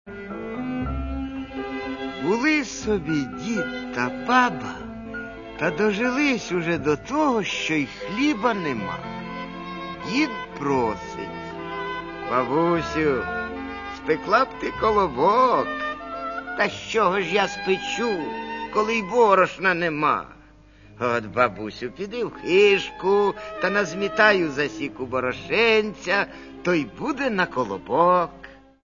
Створені вони всі одним композитором – Михайлом Чембержі, причому кожна мелодія – спеціально для тої чи іншої казки.